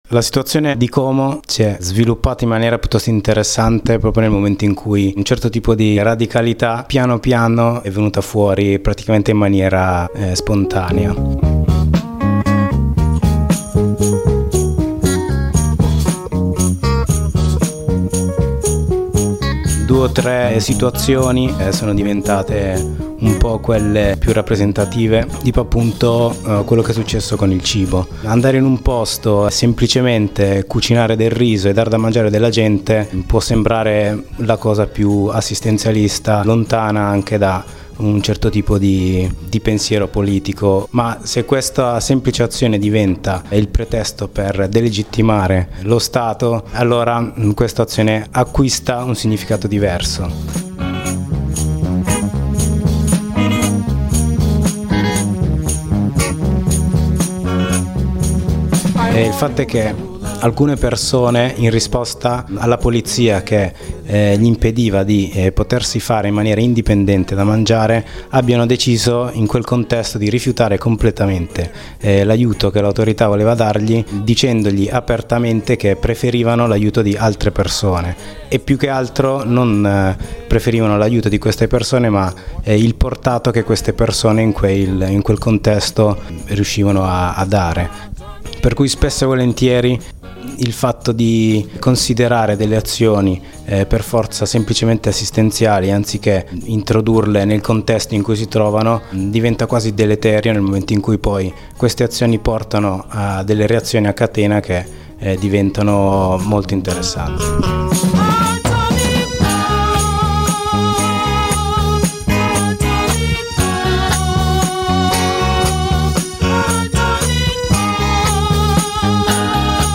L’estate scorsa a Como centinaia di migranti si sono accampati presso la stazione della città a causa dell’improvvisa riesumazione delle frontiere svizzere. In quel contesto, diversi compagni hanno deciso di intervenire, cercando di muoversi tra le maglie delle numerose difficoltà e contraddizioni di una simile situazione. Dalla voce di alcuni di loro la narrazione di questa estate comasca e qualche spunto di analisi.